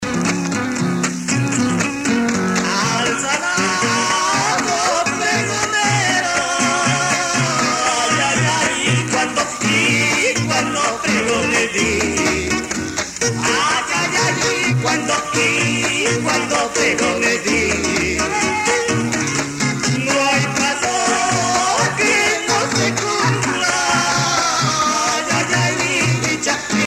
danse : Marinera (Pérou)
Pièce musicale éditée